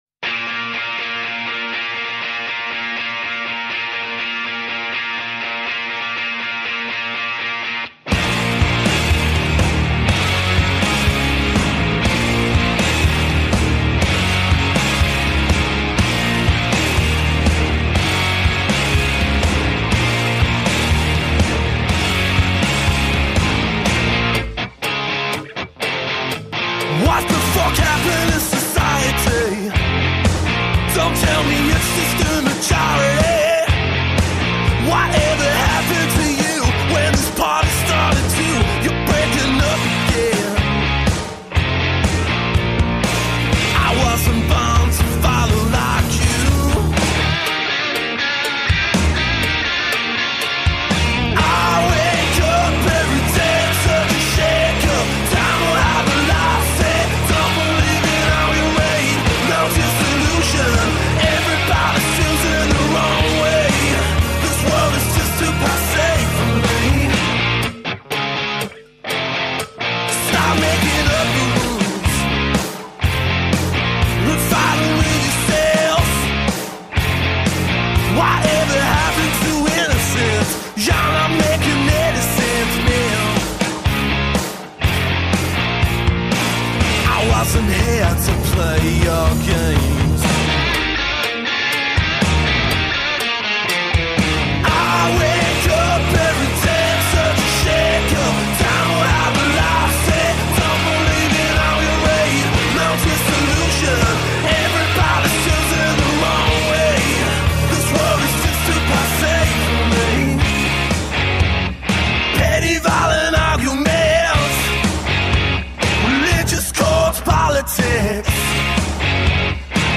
Rock and roll from the heart.
Tagged as: Hard Rock, Punk